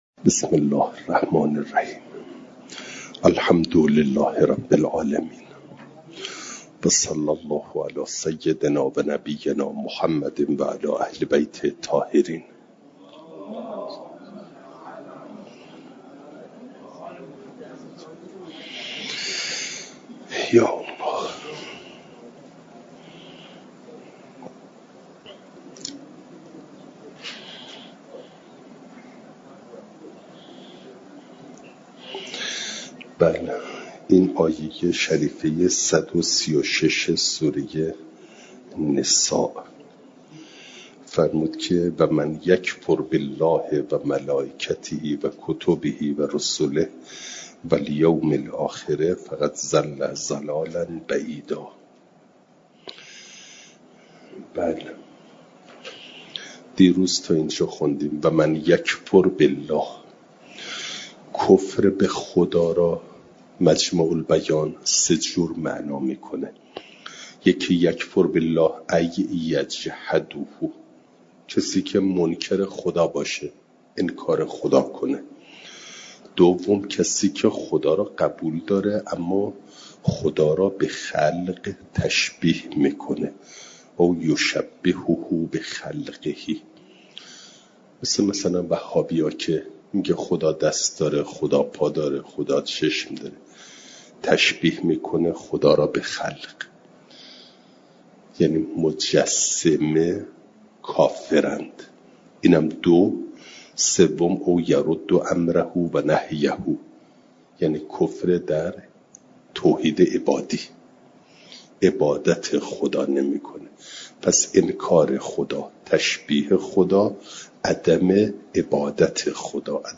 جلسه سیصد و نود و نهم درس تفسیر مجمع البیان